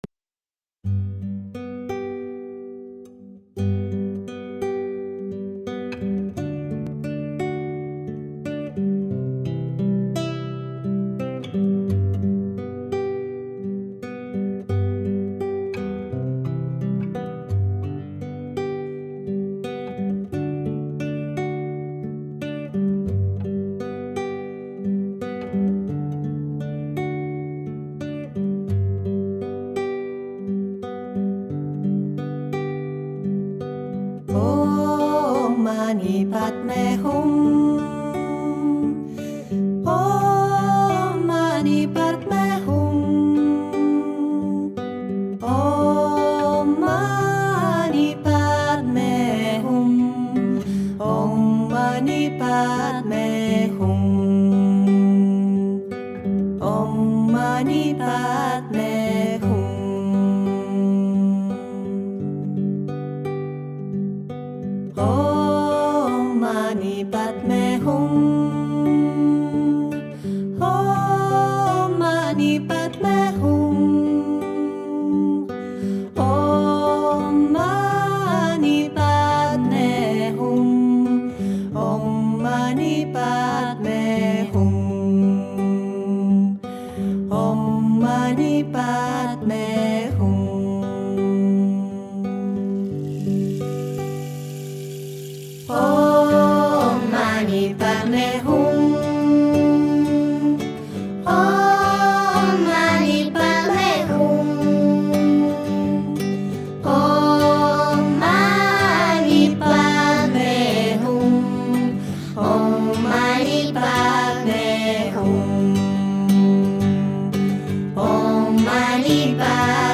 Musika lasaigarri honen bitartez, bakoitzak bere tokia topatzen du.
mantra-om-mani-padme-hum.org_.mp3